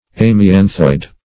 Search Result for " amianthoid" : The Collaborative International Dictionary of English v.0.48: Amianthoid \Am`i*an"thoid\, a. [Amianthus + -oid: cf. F. amianto["i]de.]